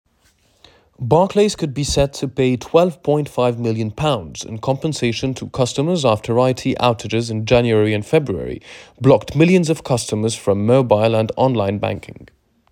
Accent Anglais